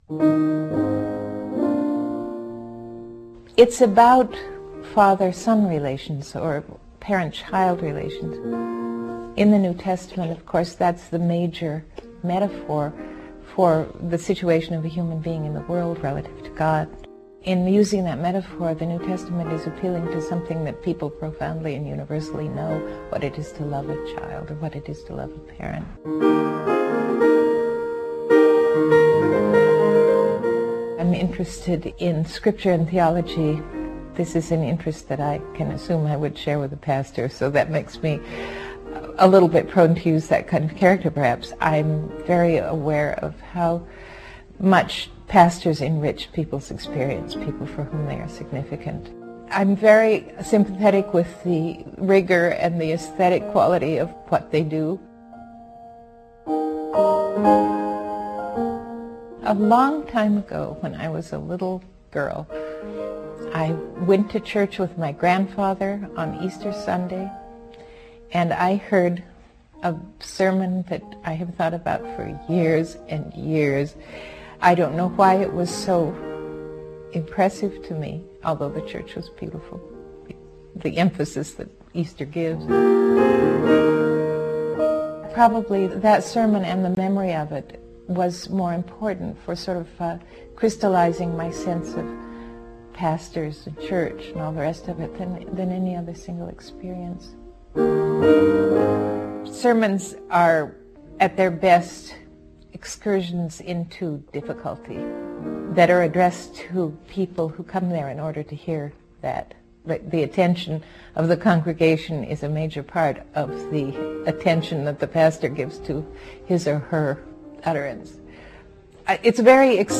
Listen to audio excerpts from our 2005 interview with Marilynne Robinson.